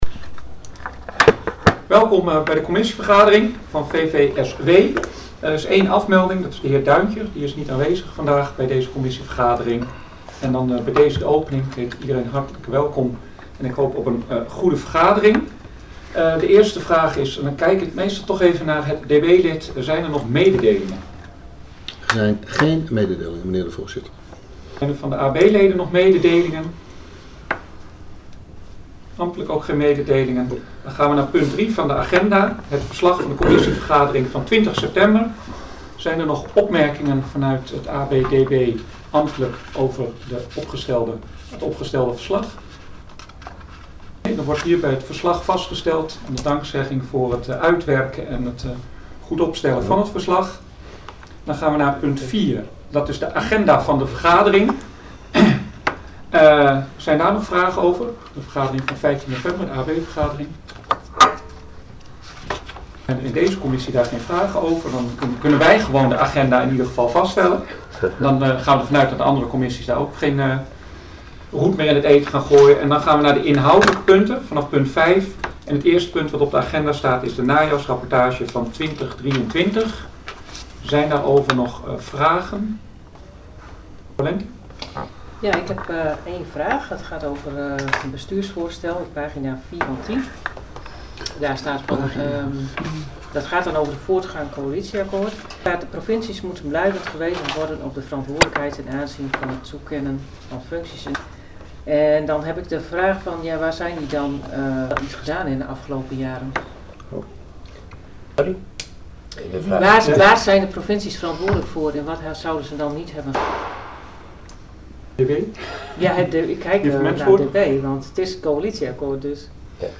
Commissievergaderingen 1 november 2023
Audio-opname-commissie-VVSW-1-november-2023.mp3